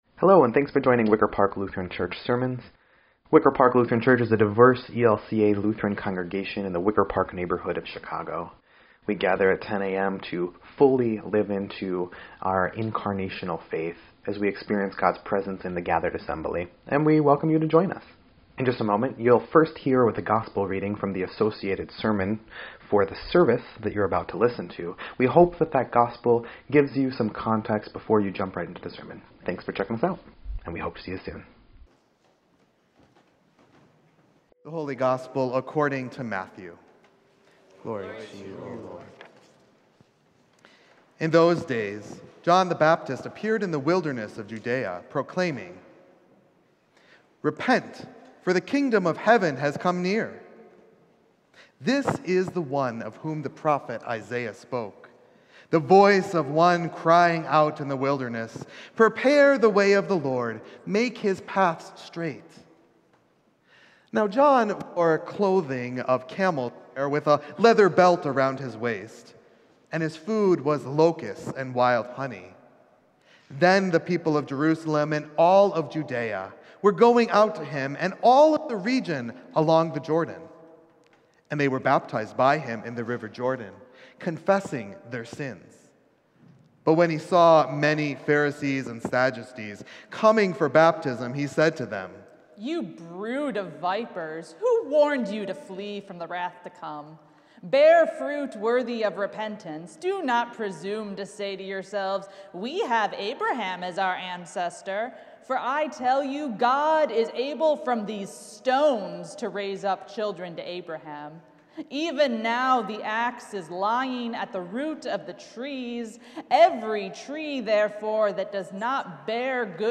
12.4.22-Sermon_EDIT.mp3